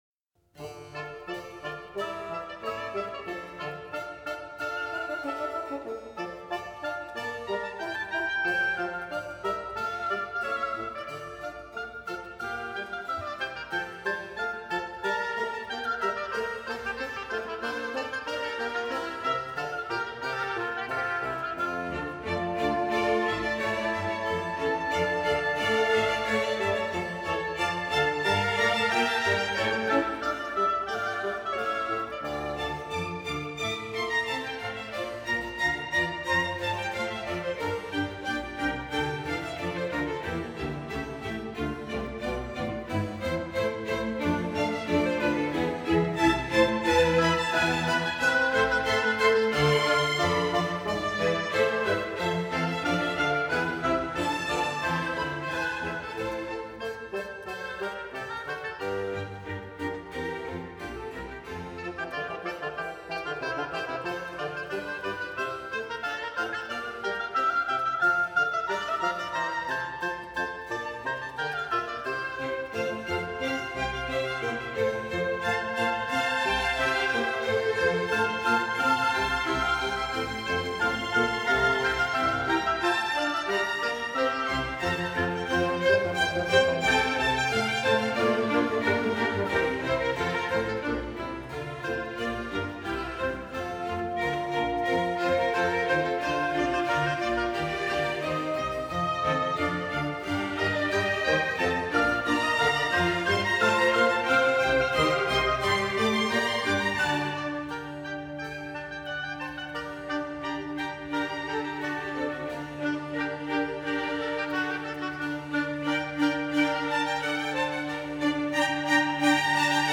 中庸的快板